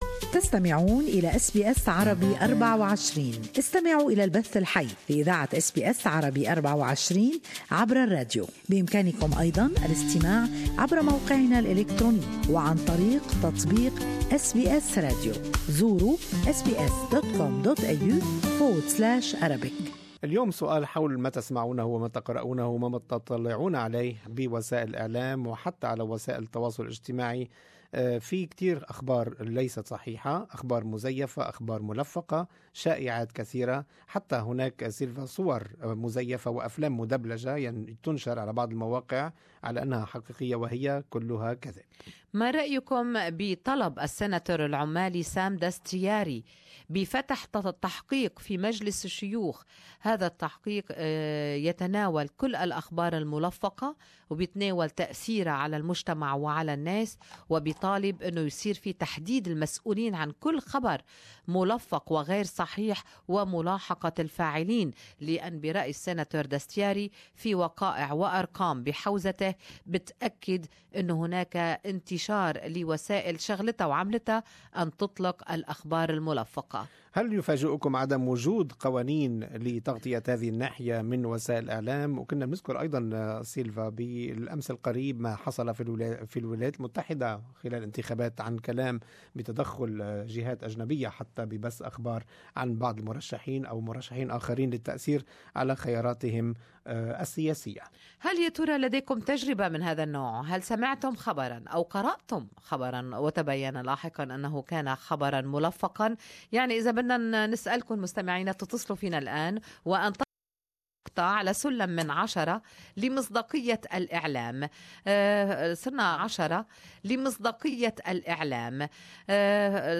Good Morning Australia listeners shared their opinion on this topic: who is behind the publishing of "Fake News"? how influential and misreading can it be? what can be done to stop it and to differentiate between what is geniune and what is not.